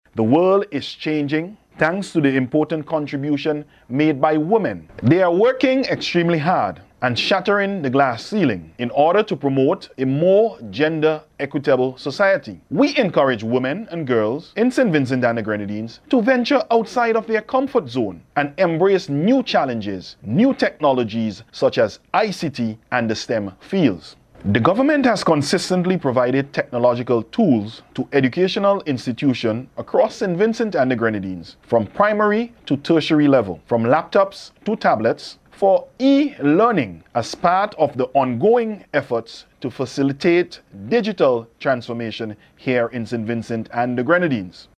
Minister Brewster challenged the women, in his message to mark International Women’s Day on Wednesday March 8th.